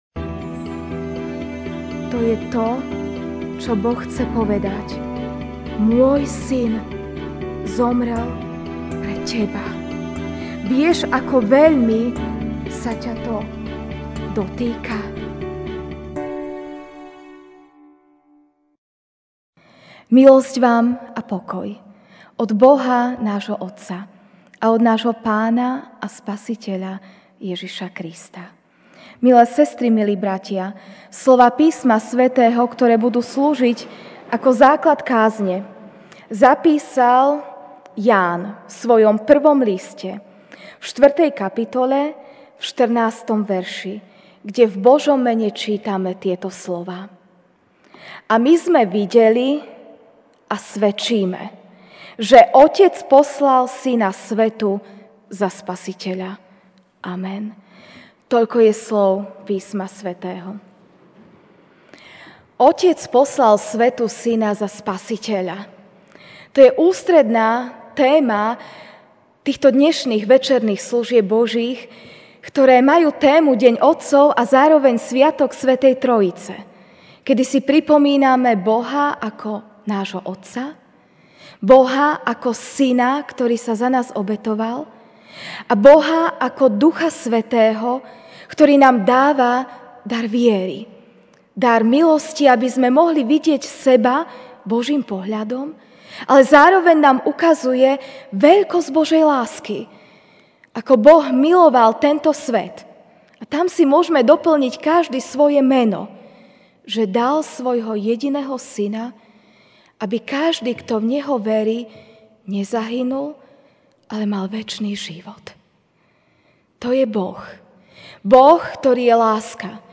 jún 16, 2019 Konanie Svätej Trojice MP3 SUBSCRIBE on iTunes(Podcast) Notes Sermons in this Series Večerná kázeň: Konanie Svätej Trojice (1J 4, 14) A my sme videli a svedčíme, že Otec poslal Syna svetu za Spasiteľa.